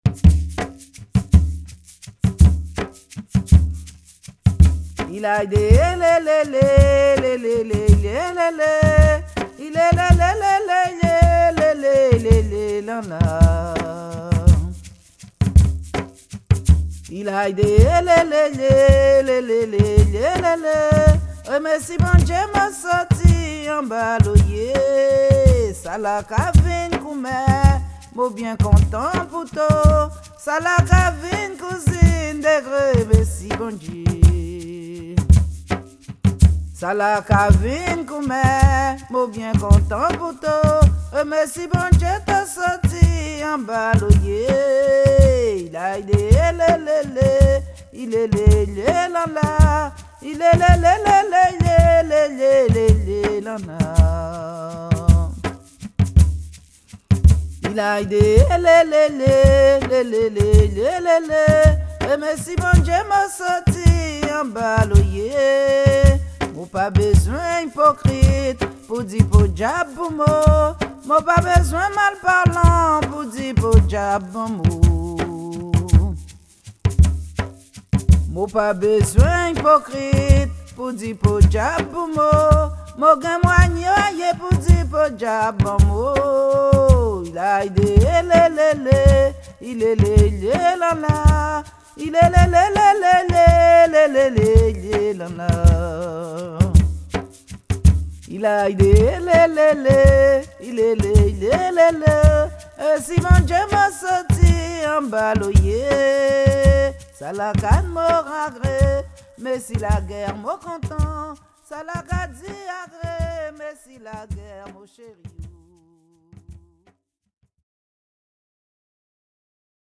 danse : bélya (créole) ; danse : grajé (créole)
Pièce musicale inédite